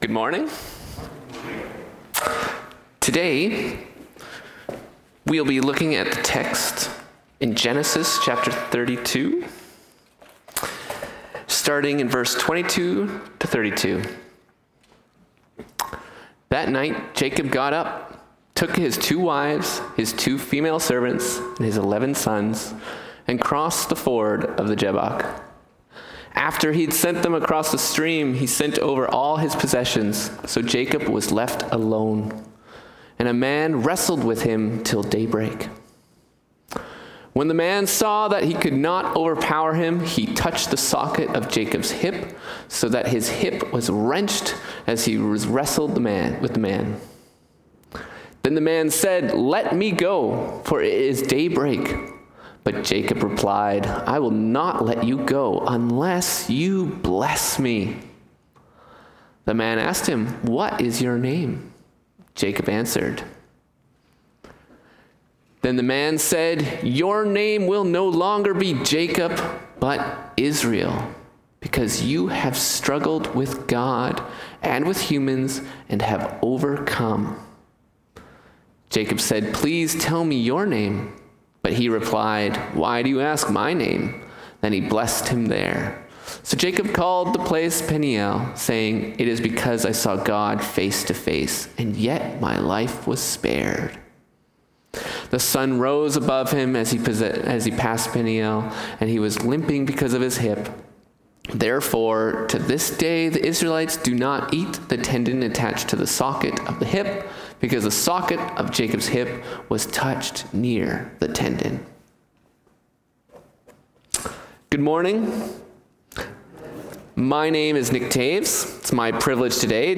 Sermons | Peace Mennonite Church